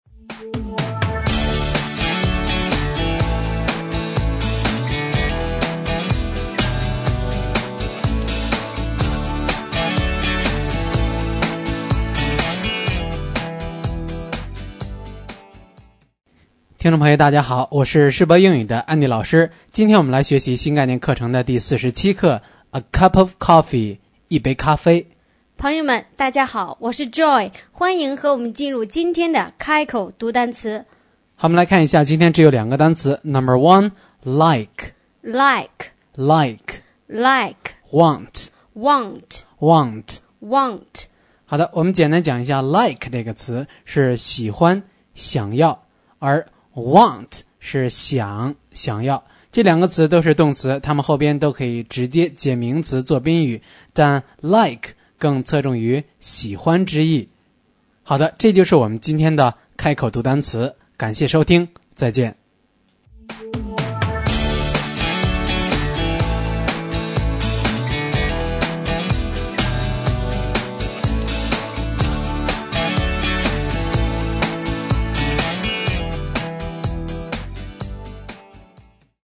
开口读单词